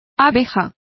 Complete with pronunciation of the translation of bee.